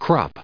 Pronunciation:
/ˈkrɑːp/